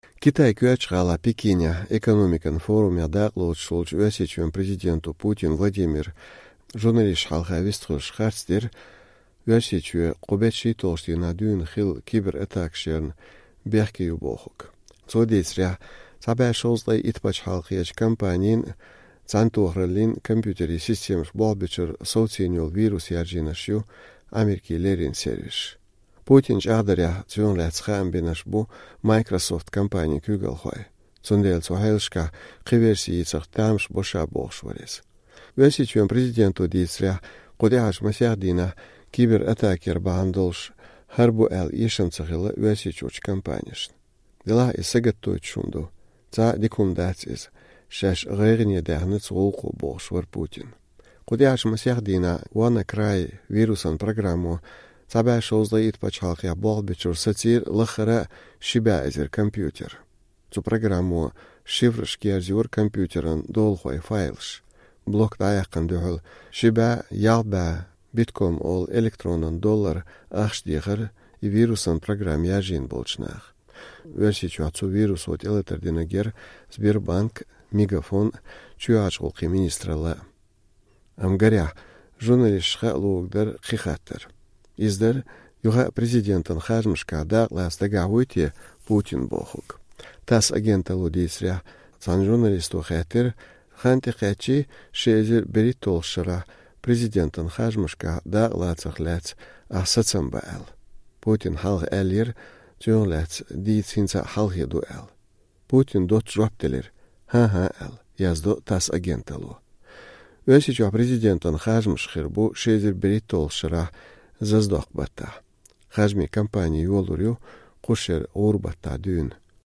Пекинехь хиллачу зорбан конференцехь журналисташна хаа луург дара, юха а президентан харжамашкахь дакъалаца дагахь вуй-те Путин бохург.
Китайн коьртачу гIалахь, Пекинехь, экономикан форумехь дакъалоцуш волчу Оьрсийчоьнан Президенто Путин Владимира журналисташна хьалха вистхуьлуш харц дира Оьрсийчоь кху беттан 12-чу дийнахь дуьйна хилла кибератакаш ярна бехке ю бохург.